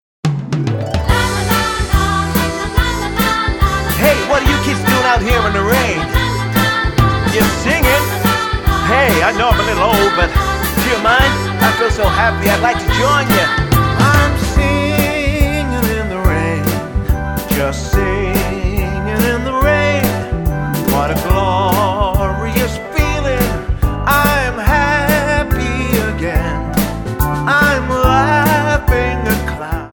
--> MP3 Demo abspielen...
Tonart:G-Ab Multifile (kein Sofortdownload.